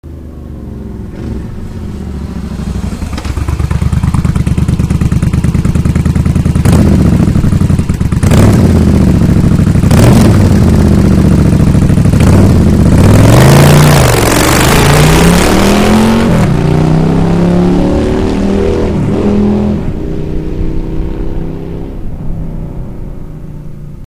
U výfuků 2 si můžete 3x poslechnou zvuk